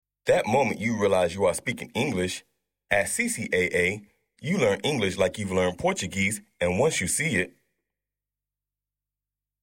Male
English (North American)
Adult (30-50)
I have a strong, distinctive, baritone voice that will bring life to your project.
E-Learning
Short 10 Sec. Voiceover
All our voice actors have professional broadcast quality recording studios.